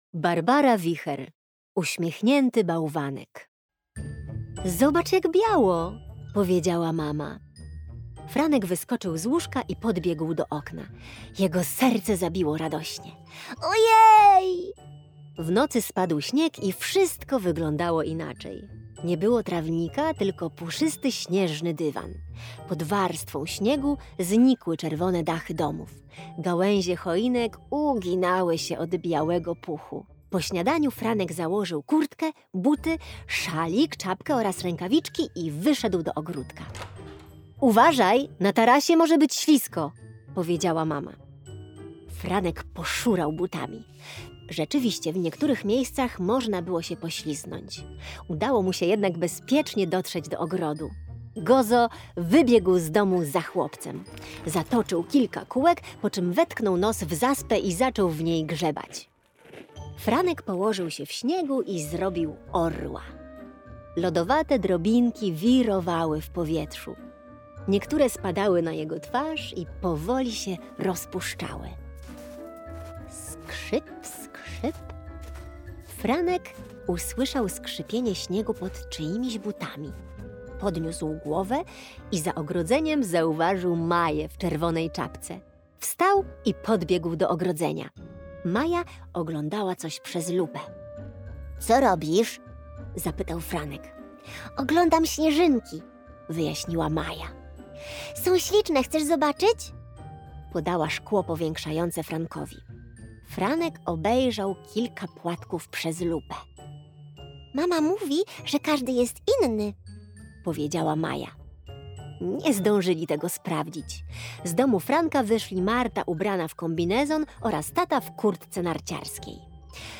opowiadanie „Uśmiechnięty bałwanek” - EDURANGA